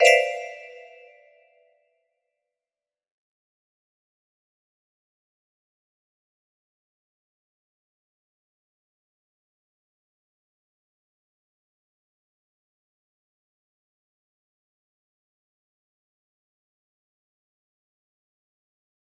Music Box Paper Strip DIY 30 Note music box melody